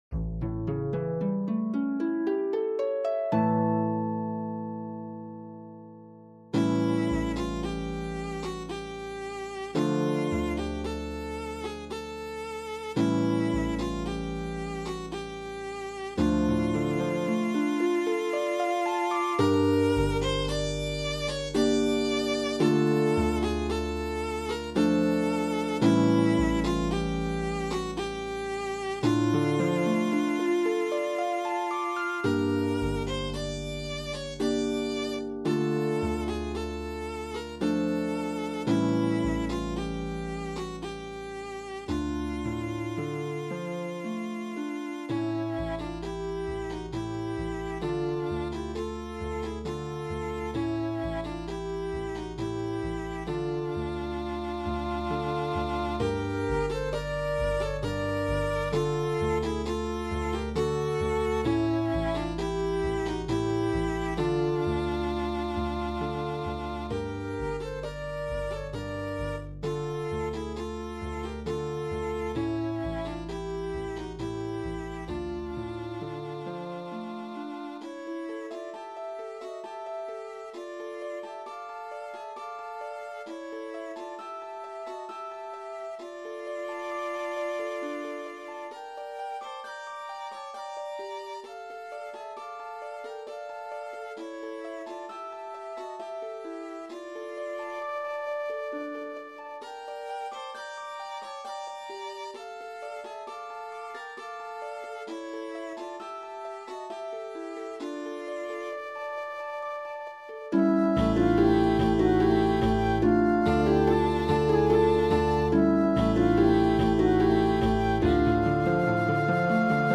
two traditional South American folk melodies
pedal harp, flute, and viola
Uruguayan folk tune
Paraguayan folk tune